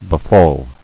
[be·fall]